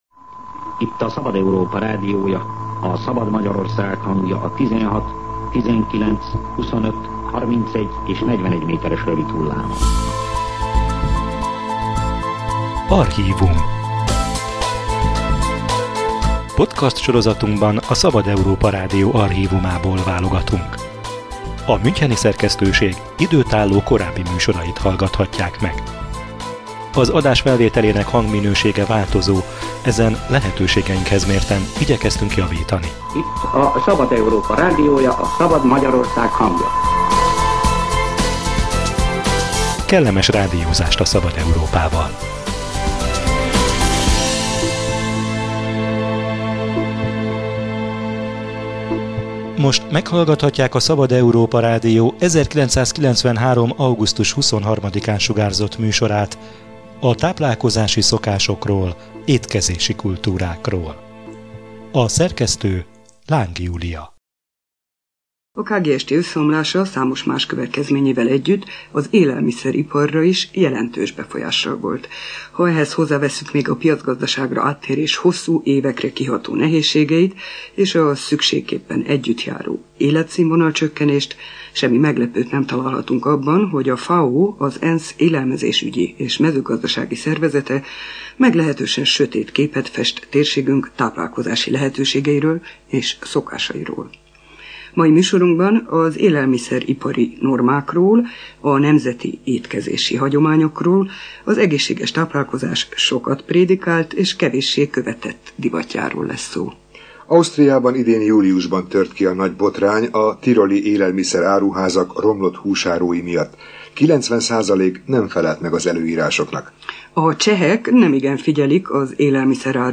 Testkontroll és salátabár – 1993-ban még csak ízlelgettük ezeket a szavakat, még nem váltak a napi étkezési szokások részévé ezek az igények. A Szabad Európa Rádió archív műsora körképet mutat a kilencvenes évek elejének táplálkozási szokásairól és étkezési kultúrájáról